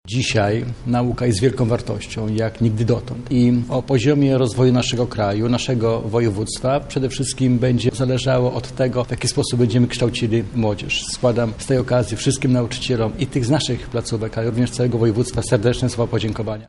– mówi Krzysztof Grabczuk, wicemarszałek województwa lubelskiego.